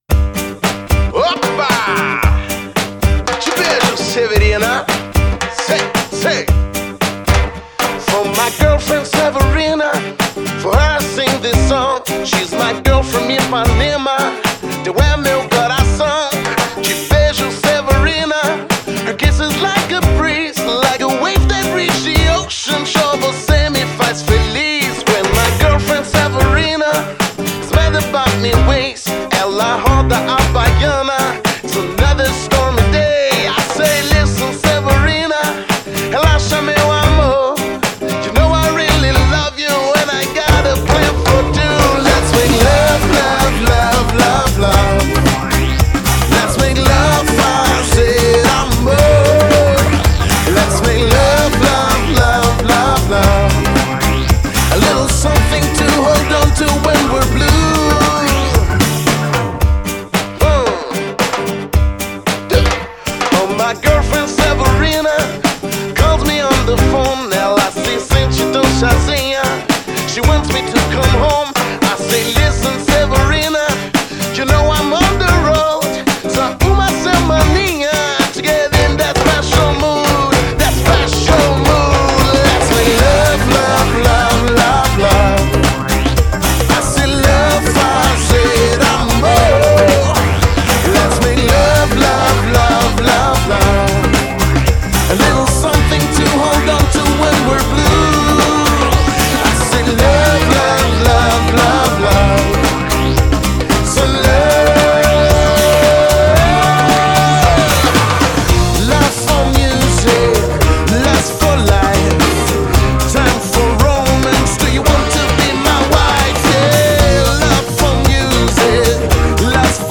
Brazilian rhythms, R&B, soul, funk, pop.